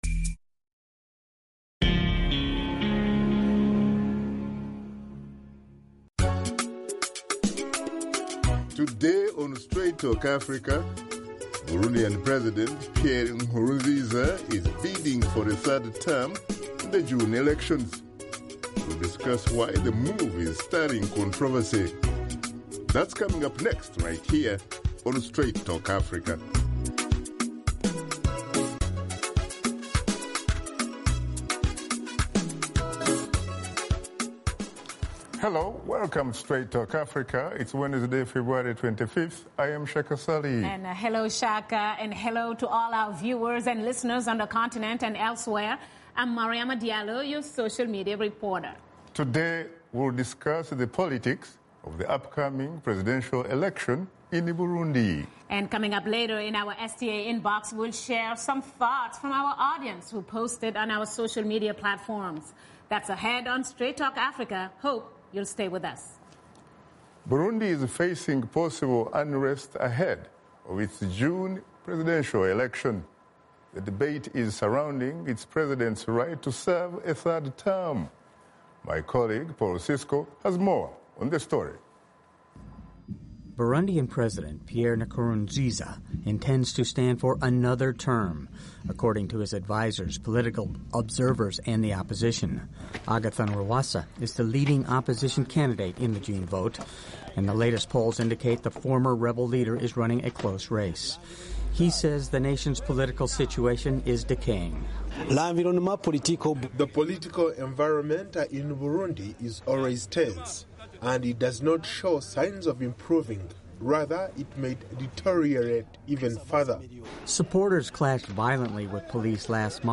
Host Shaka Ssali and his guests discuss President Pierre Nkurunziza’s controversial decision to seek a third term.